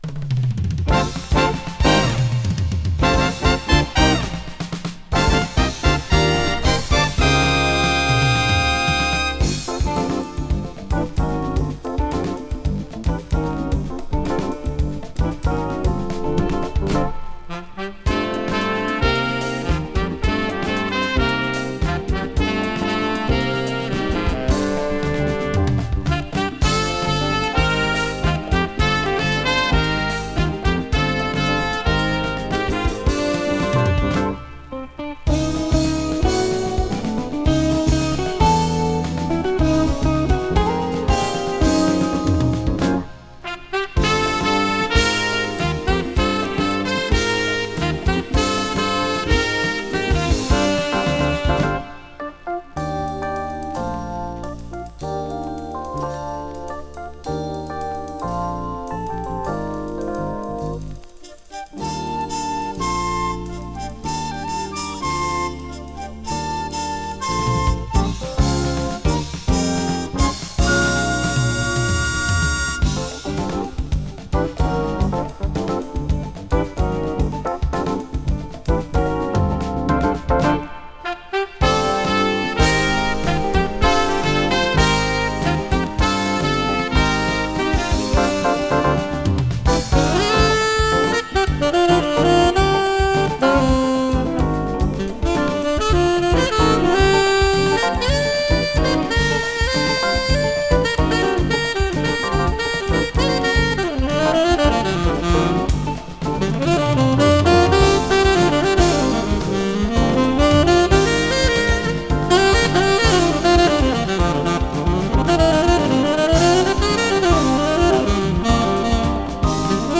【JAZZ FUNK】 【FUSION】
L.A.産オブスキュア・フュージョン！
ウエストコーストらしいブリージンなサウンドながらも、絶妙なインディ臭さ漂うオブスキュアなフュージョン・ナンバーを収録！